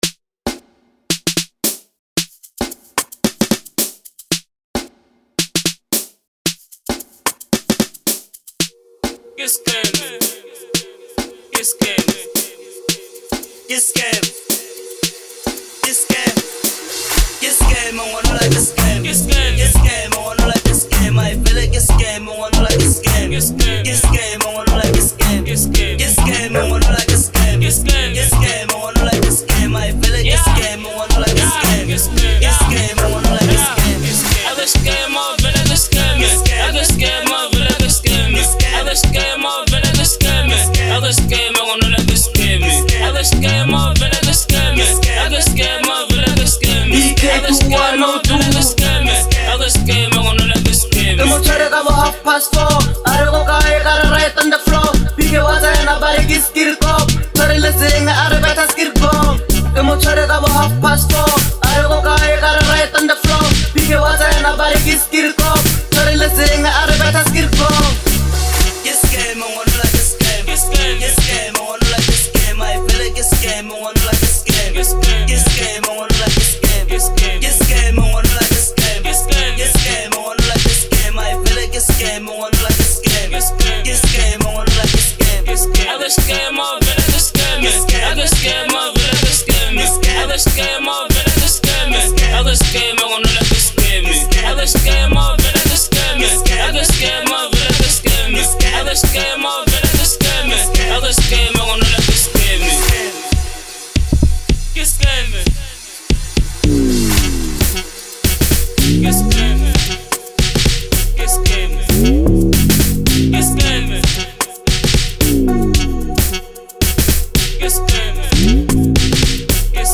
06:21 Genre : Amapiano Size